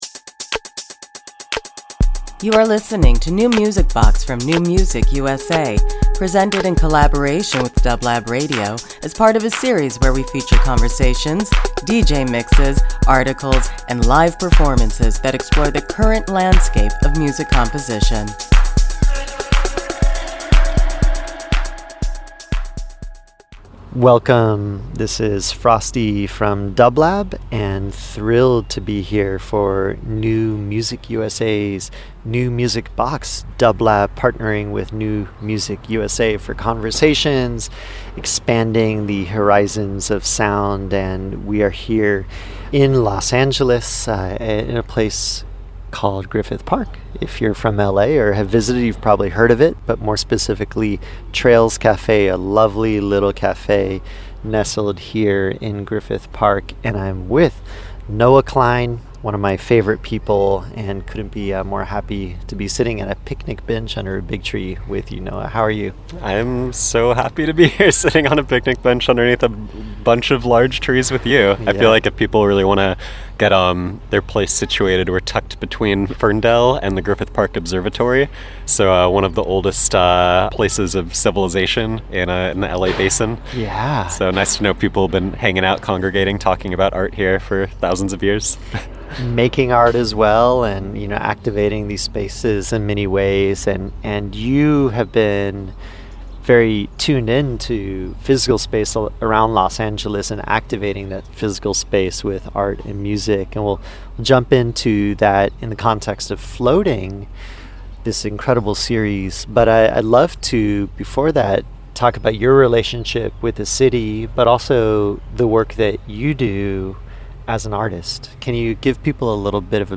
Interview
Their conversation took place under a canopy of trees at Trails Cafe, in Griffith Park over a chickpea salad sandwich and cold beverages.